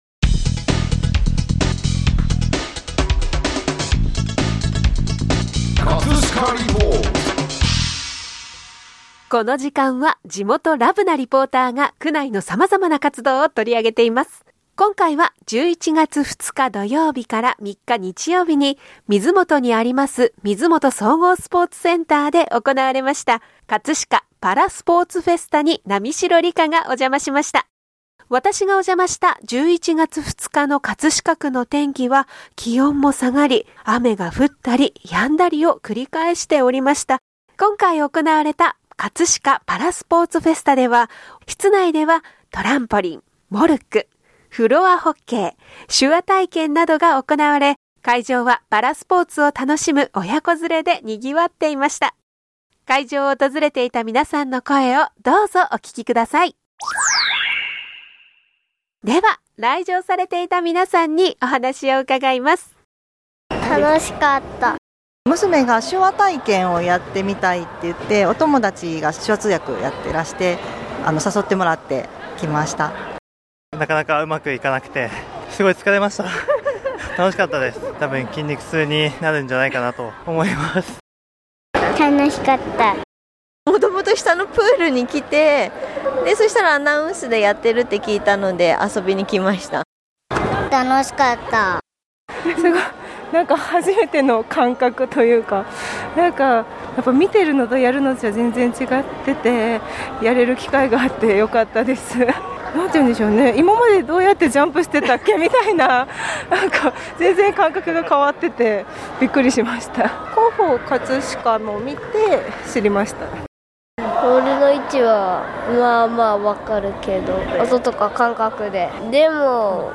屋外では、ブラインドサッカー日本選手権予選、そして室内ではトランポリン、モルック、フロアホッケー、手話などの体験が行われ、会場はスポーツを楽しむ親子連れでにぎわっていました。 会場を訪れていた皆さんの声をどうぞお聴き下さい！